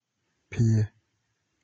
A Phie (/pʰiɛ̯³³/